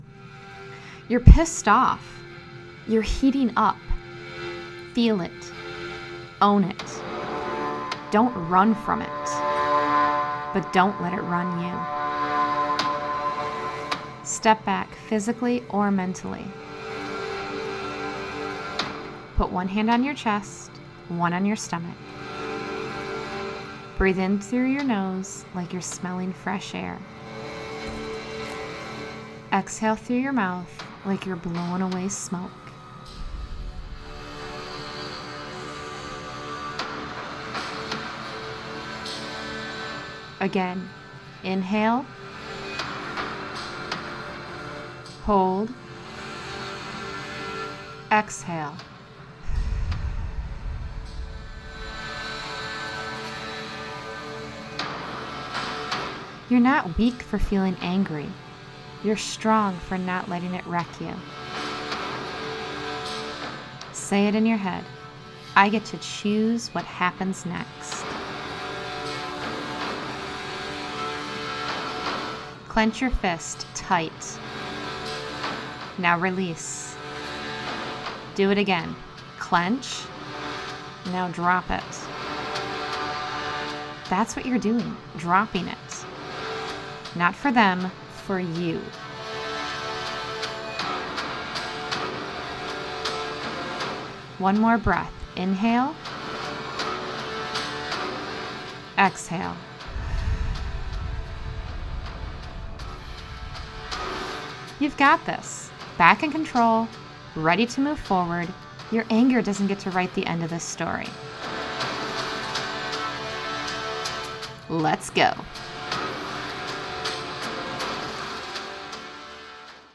Guided Audio Emotional Resets